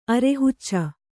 ♪ arehucca